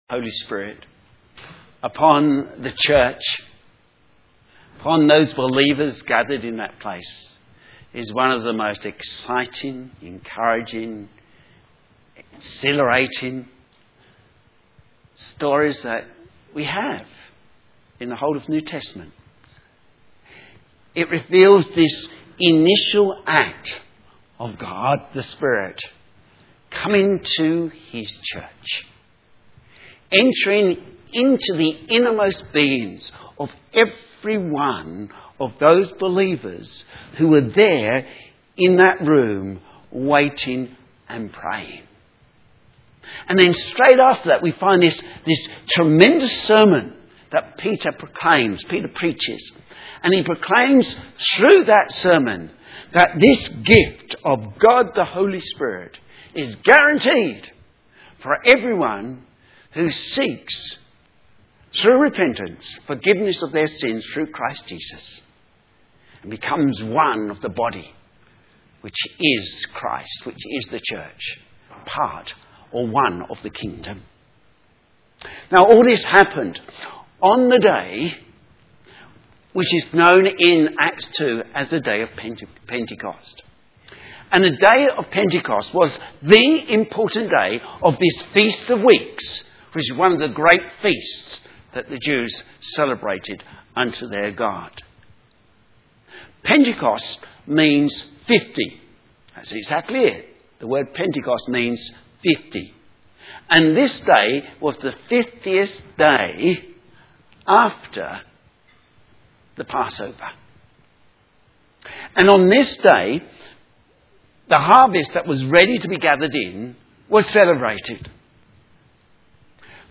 Sermon
Pentecost Acts 2:1-11 Synopsis Preached on Pentecost Sunday this message considers the account of the first Pentecost and how it applies to believers today.